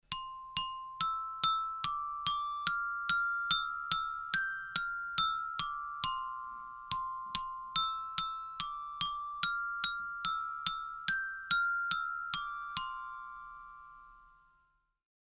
Melodía sencilla interpretada con un carillón
idiófono instrumento musical percusión campanilla carillón